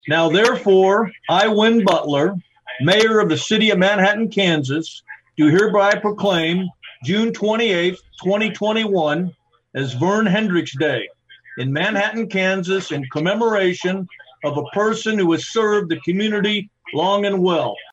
read today live on KMAN by Mayor Wynn Butler.